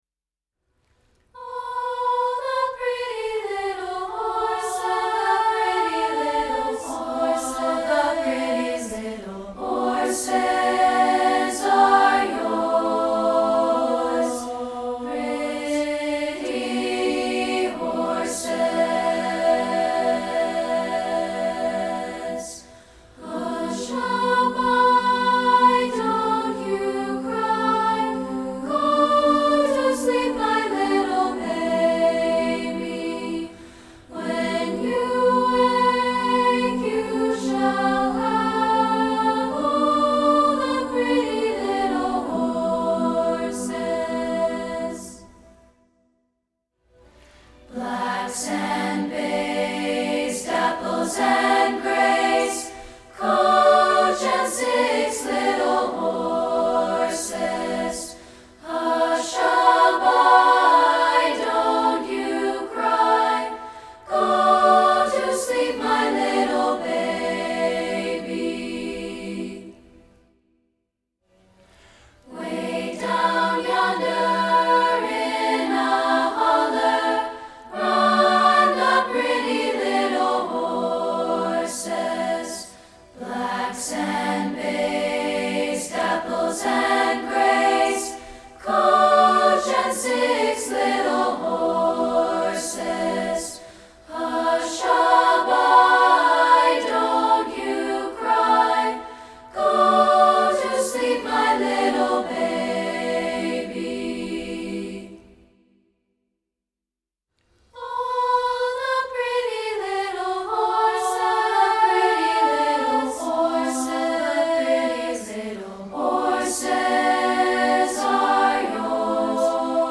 including this a cappella version.